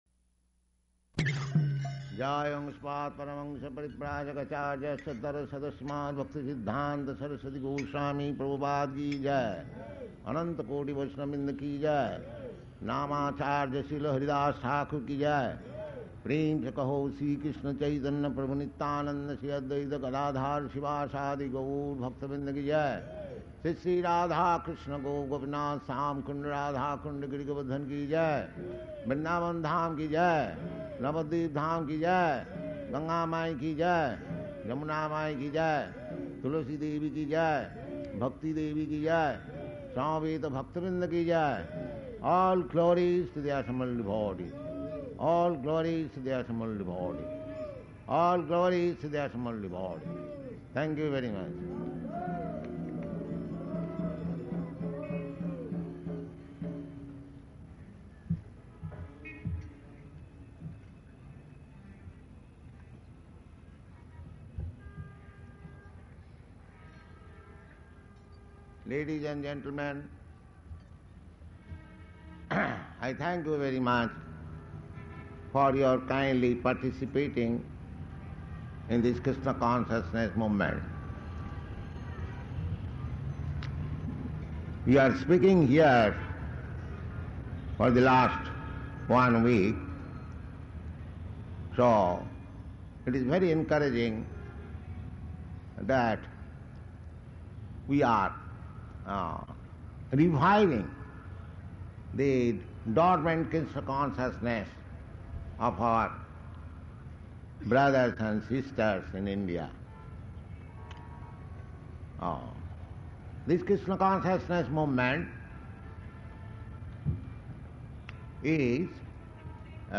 Paṇḍāl Lecture
Type: Lectures and Addresses
Location: Delhi